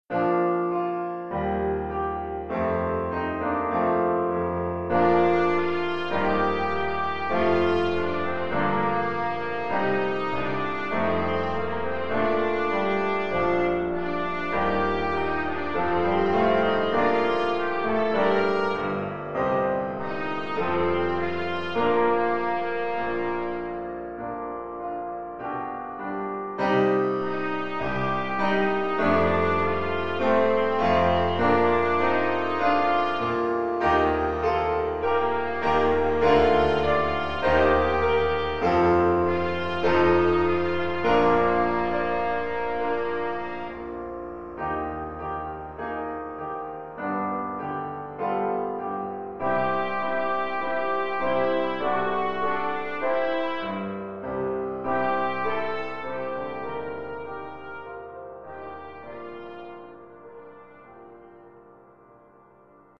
Oeuvre pour trompette sib ou ut
ou cornet ou bugle et piano.